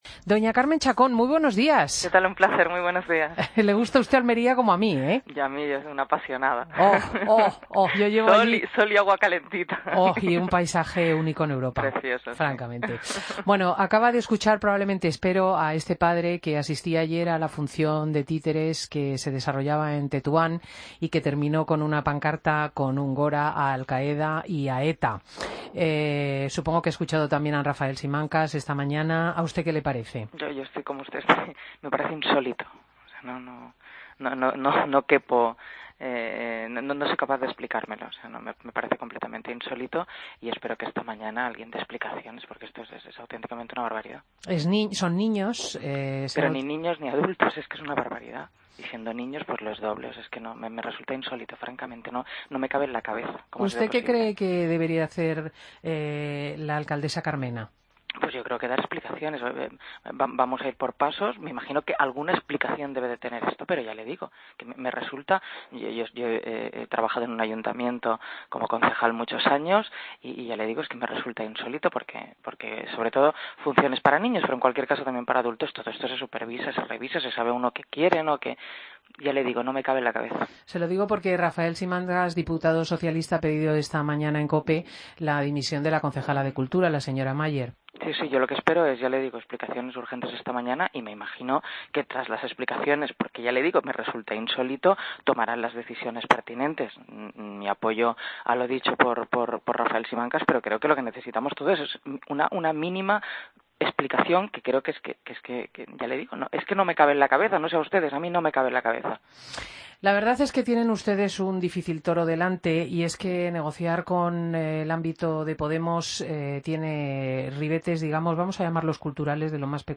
Entrevista a Carme Chacón, miembro de la Ejecutiva Federal del PSOE, en Fin de Semana Cope.